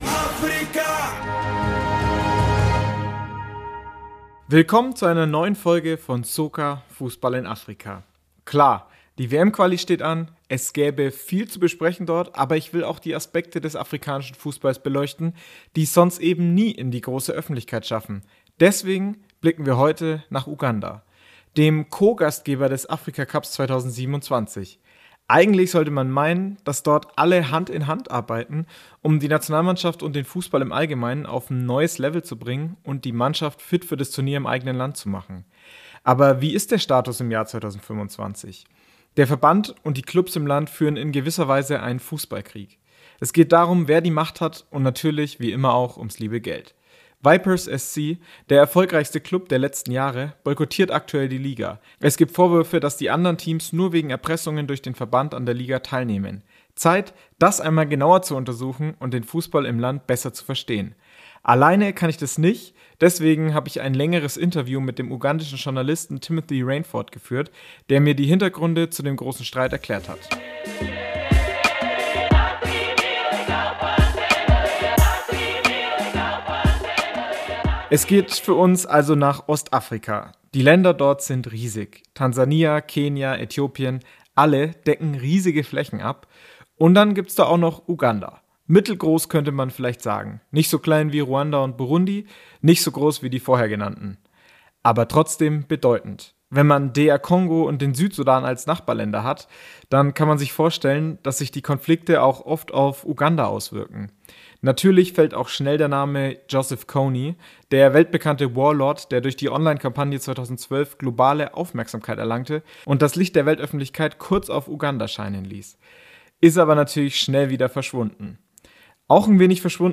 Beschreibung vor 6 Monaten Vipers SC und ihr mächtiger Präsident Dr. Lawrence Mulindwa proben gerade den Aufstand im Fußball Ugandas. Sie wollen das neue Liga-Format verhindern und sprechen dabei auch von Epressung und Bestechung gegenüber anderen Vereinen durch den Verband. Ich habe mit Journalist